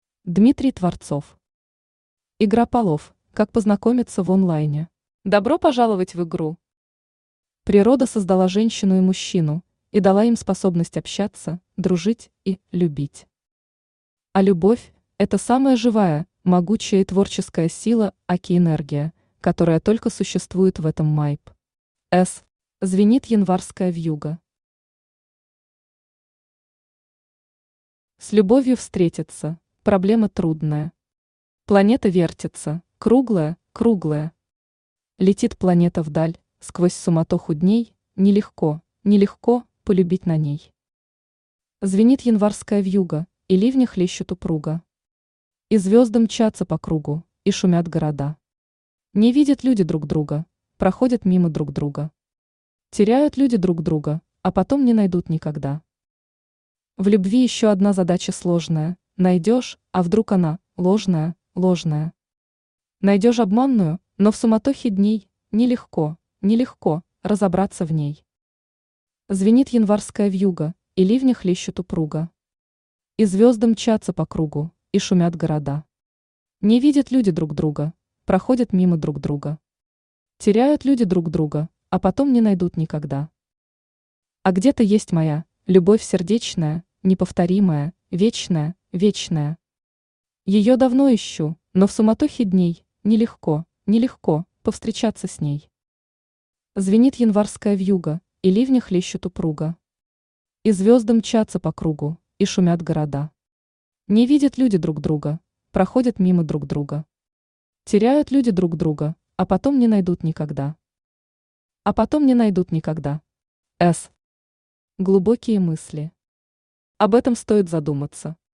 Аудиокнига Игра полов – как познакомиться в онлайне | Библиотека аудиокниг
Aудиокнига Игра полов – как познакомиться в онлайне Автор Дмитрий Творцов Читает аудиокнигу Авточтец ЛитРес.